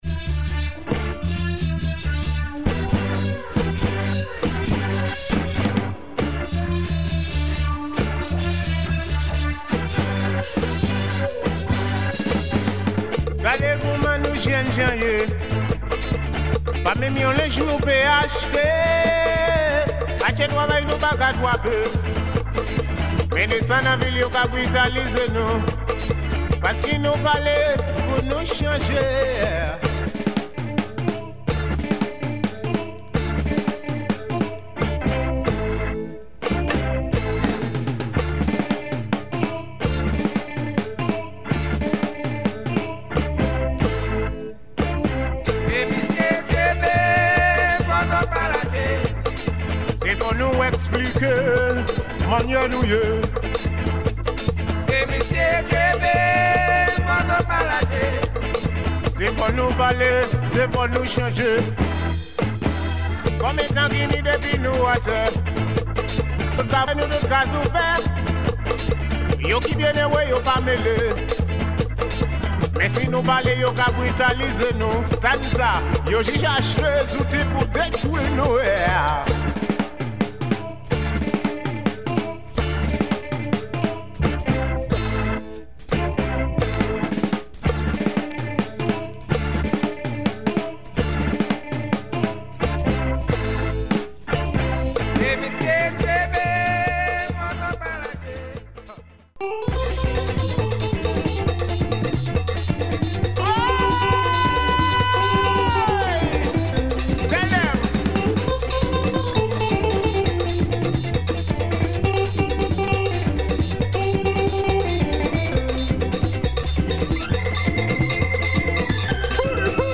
Rare spiritual gwo ka and deep reggae